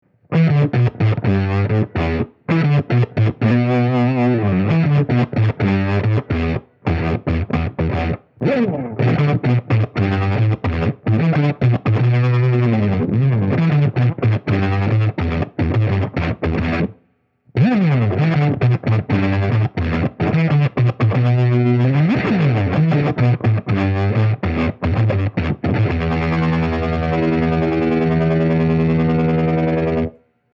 tremvelope is a tremolo pedal that allows the dynamics of your playing to change the speed and depth of the effect. the result is an intuitive, all analog sound that takes a classic stompbox concept to a whole new level.
tremvelope achieves a stimulating state of sonic flux by altering both speed and depth in sync with what you are playing. the resulting psycho-acoustic effects are sometimes reminiscent of the leslie rotating speaker cabinet, whose signature sound is most pronounced when the rate of its speaker’s rotation is changing. the continual changes in rate and depth achieved by the tremvelope are highly interactive with the musician. since the tremvelope is sensitive to what you play and you are sensitive to how your music sounds, there is an instantaneous feedback between the motion of the tremolo and your motions on the instrument.
sine and sawtooth wave options
mono or stereo with panning output